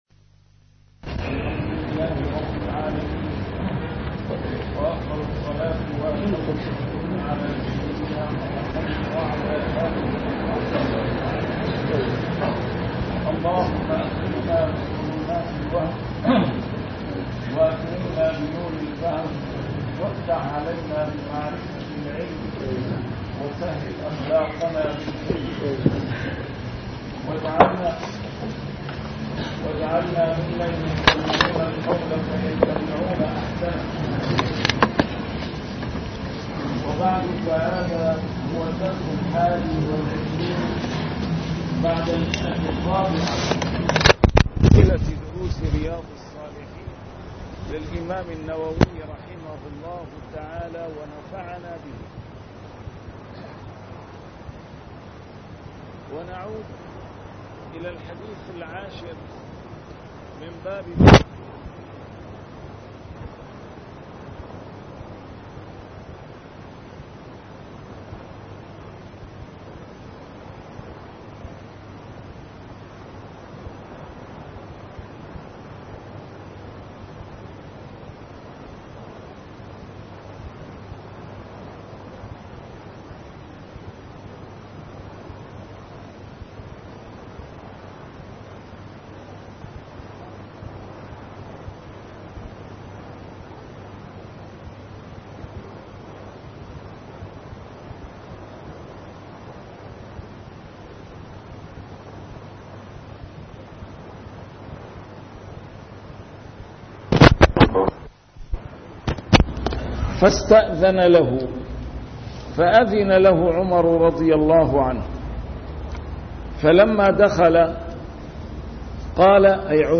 A MARTYR SCHOLAR: IMAM MUHAMMAD SAEED RAMADAN AL-BOUTI - الدروس العلمية - شرح كتاب رياض الصالحين - 421- شرح رياض الصالحين: توقير العلماء